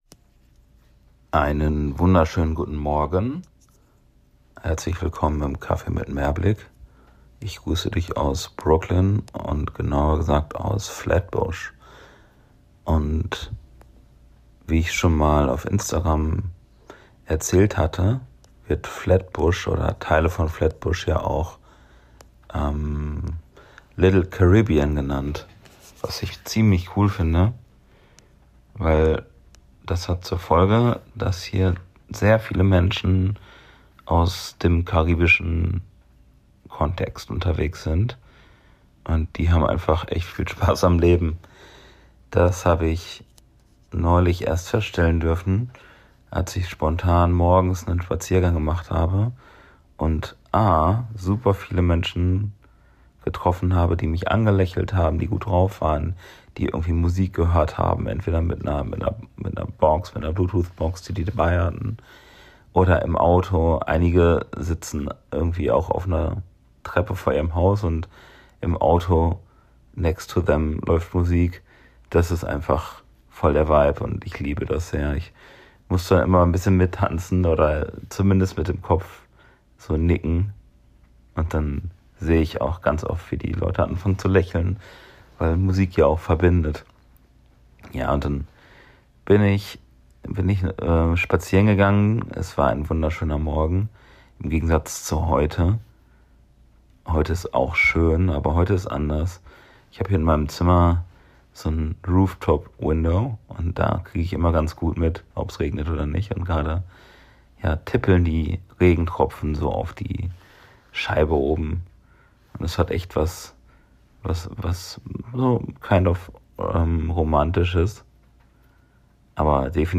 Bei einem kleinen morgendlichen Spaziergang durch die neighborhood,